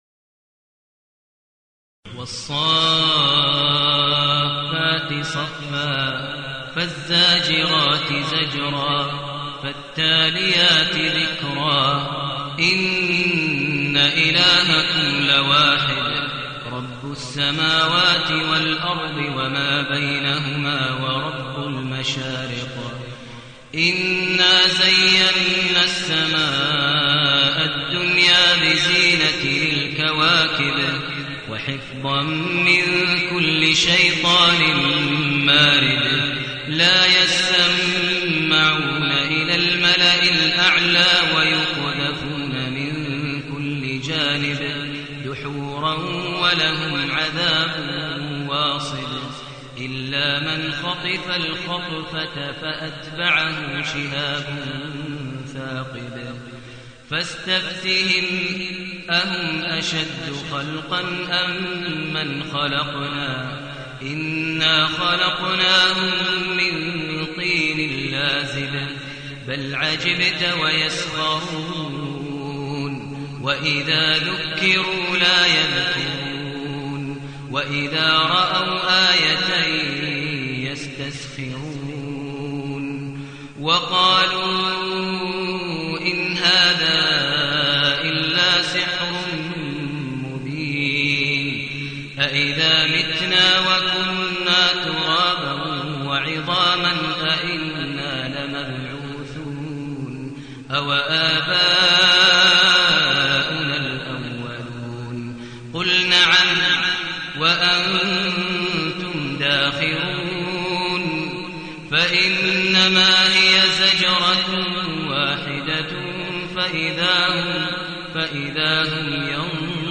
المكان: المسجد الحرام الشيخ: فضيلة الشيخ ماهر المعيقلي فضيلة الشيخ ماهر المعيقلي الصافات